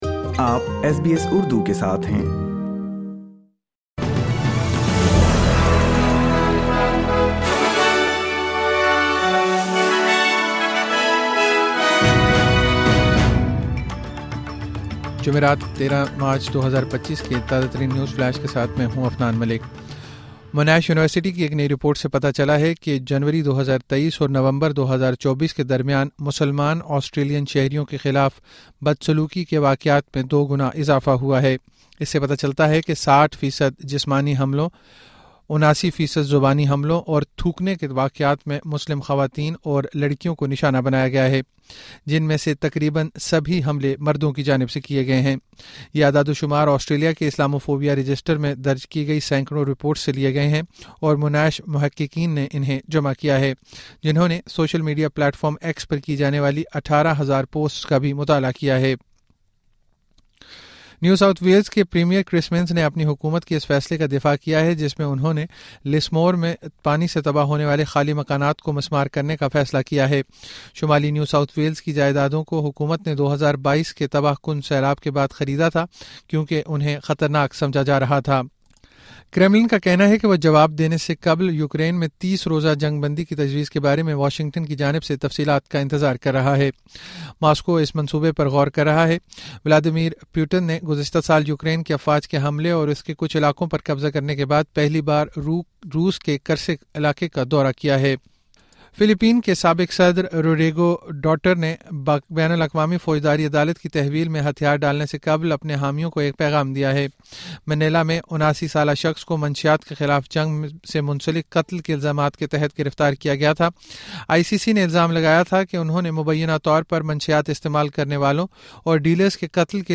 مختصر خبریں: جمعرات 13 مارچ 2025